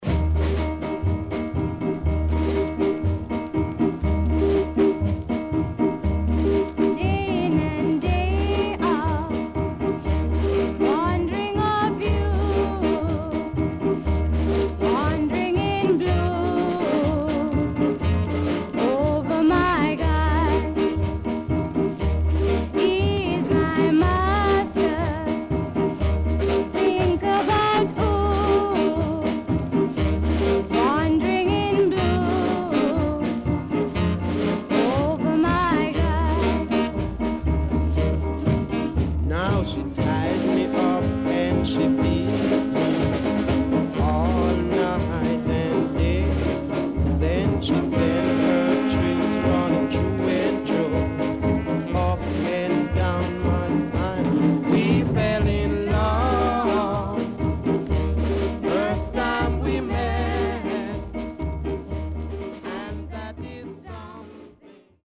HOME > SKA / ROCKSTEADY  >  SKA  >  EARLY 60’s
Early Duet Ska Vocal
SIDE A:所々チリノイズがあり、少しプチノイズ入ります。